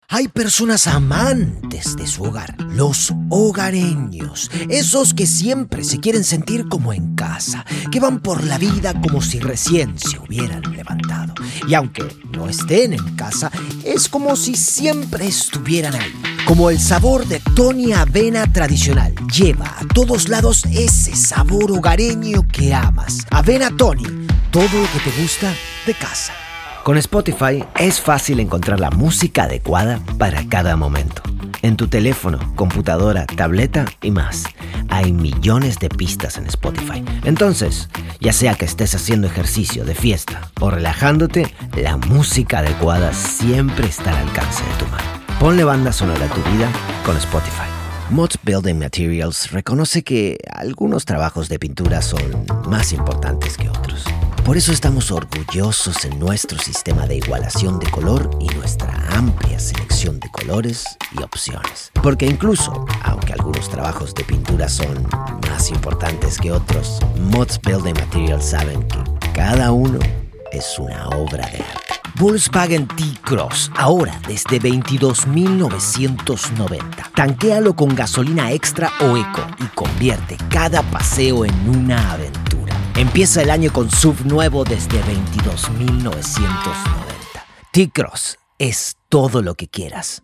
Espagnol (argentin)
Narration médicale